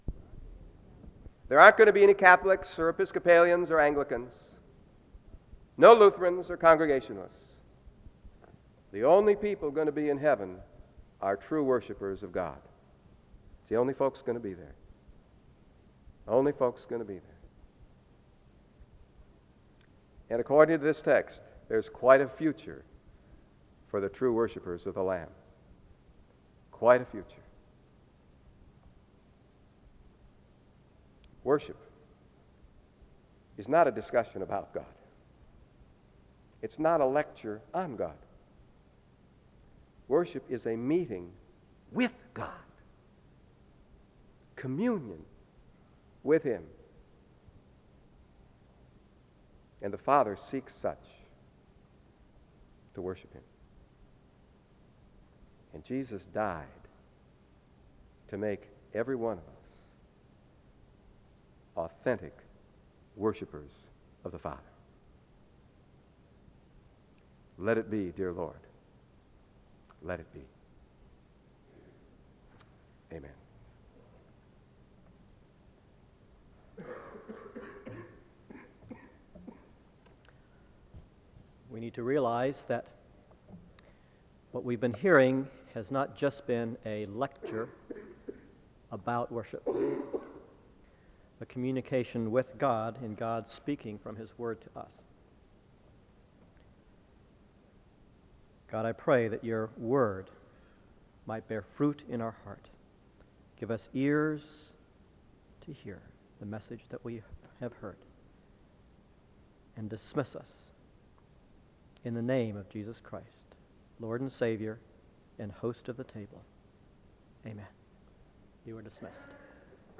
SEBTS Adams Lecture